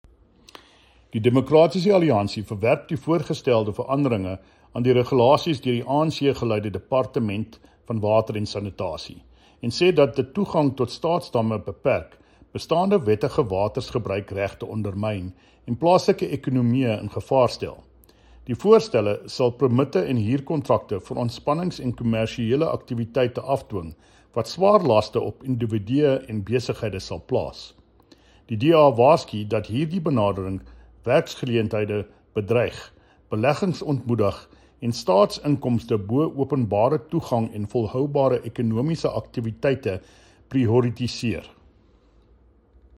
Afrikaans soundbites by David Mc Kay MPL and